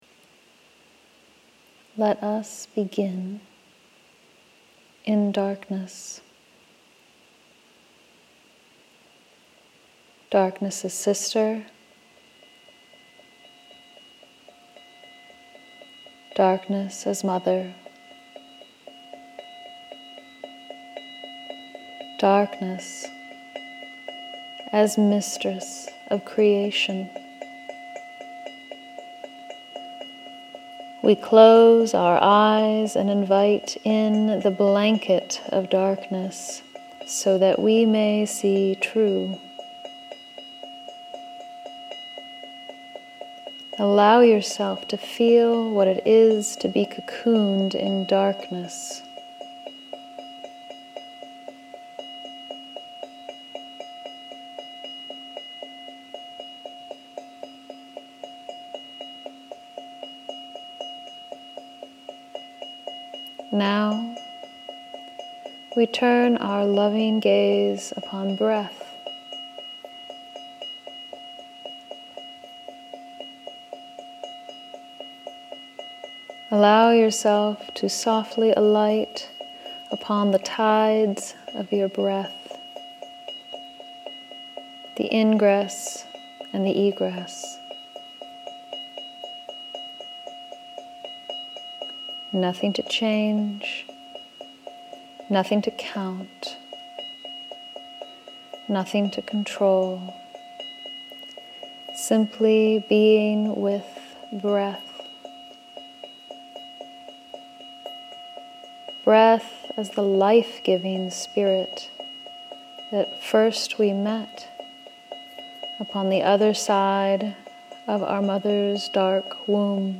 Homework: Download and listen to the Knowledge Lecture.